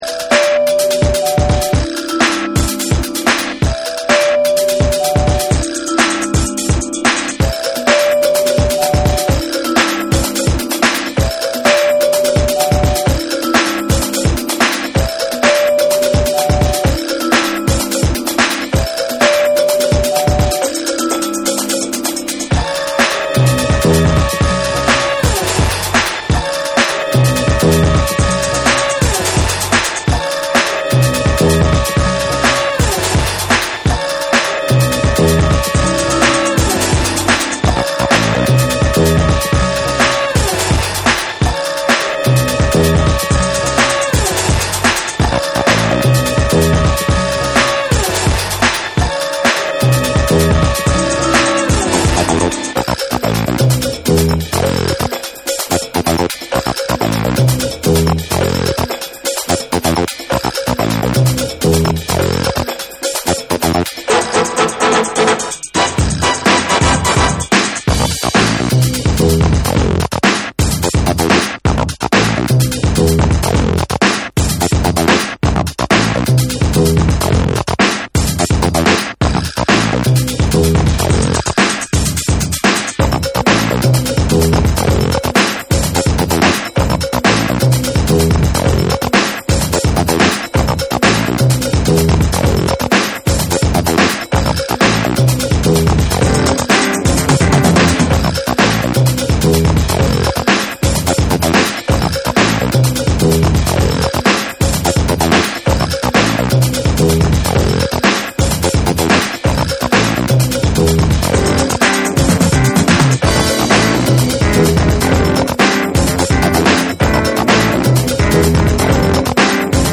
BREAKBEATS / REGGAE & DUB